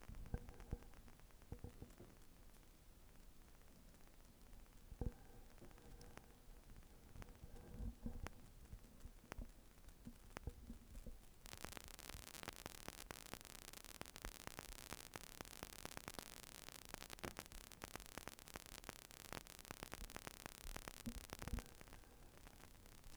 Andernfalls kann es zu sehr unschönen Störgeräuschen kommen.
Störsound bei der Verwendung von Bluetooth mit einer Apple Watch